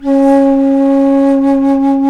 FLT ALTO F01.wav